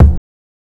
KICKPUNCH3.wav